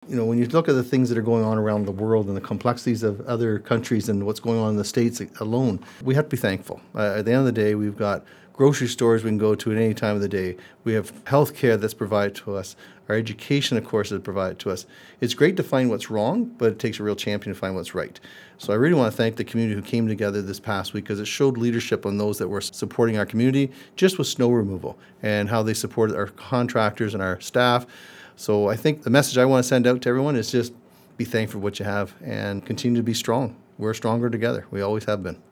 As we look to the new year, South Huron Mayor George Finch stopped by the myFM studio and highlighted the community’s accomplishments in 2024, reflecting on a year filled with resilience, collaboration, and growth.